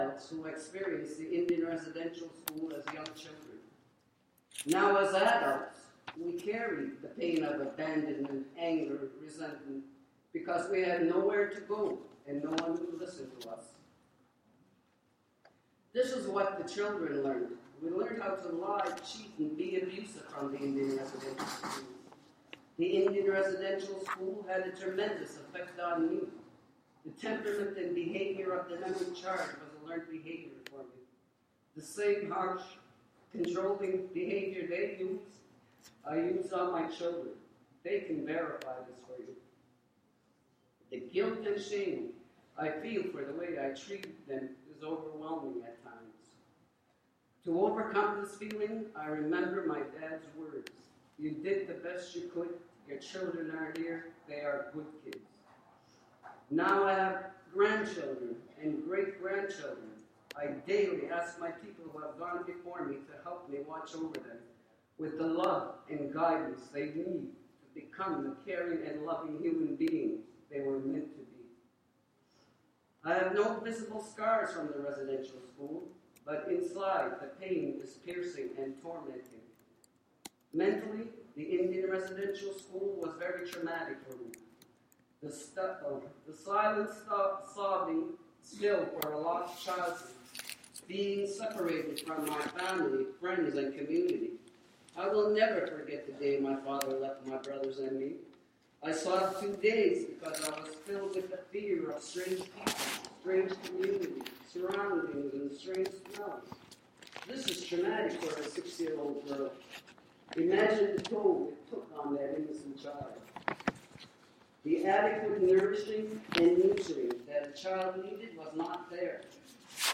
I came in after she began her testimony, but what I’ve recorded here is very moving. I also didn’t have time to get my proper recorder operating— I apologize the quality isn’t great on this.